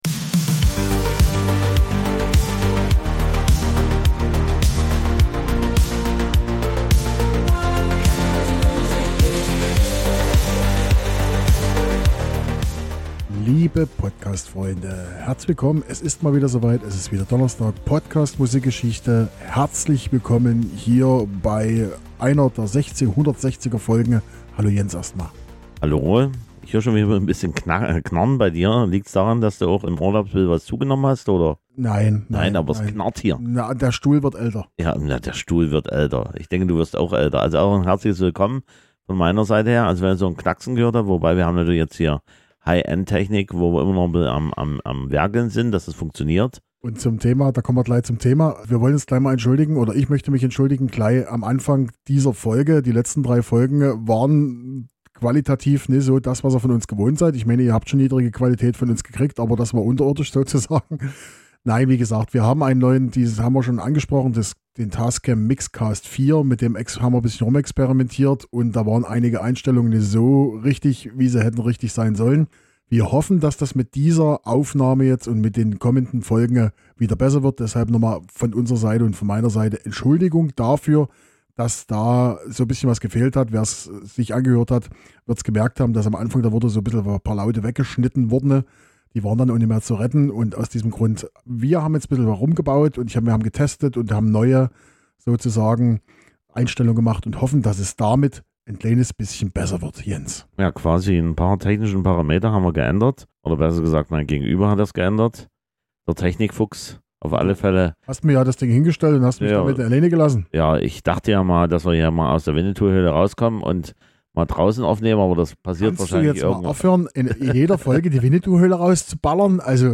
Ein One-Hit-Wonder, zweimal Musik die 1995 ihre Hochphase hatte und ein Song, der damals Platz 1 in Deutschland - und das völlig berechtigt - erreichte. Die Technikprobleme scheinen nun beseitigt zu sein, jedoch macht diesmal ein Stuhl unseren Podcasthelden zu Schaffen zu machen.